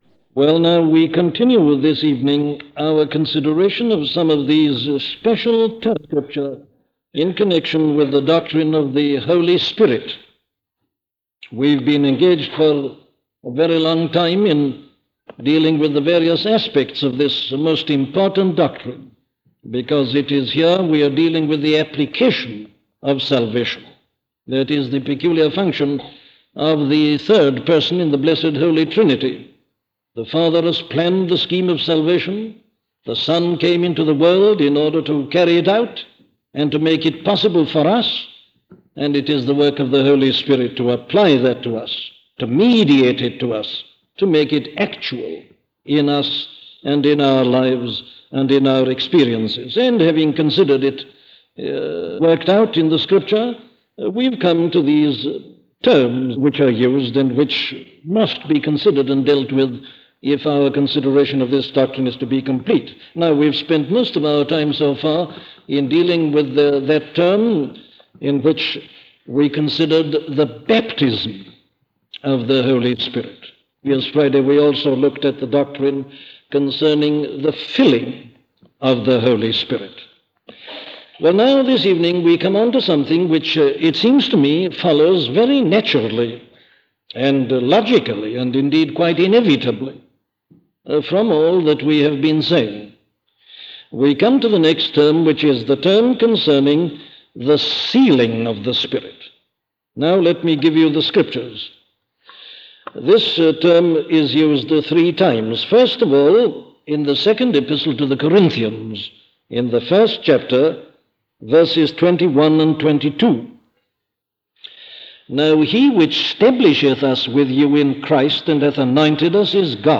Listen to the sermon 'The Sealing of the Holy Spirit' by Dr. Martyn Lloyd-Jones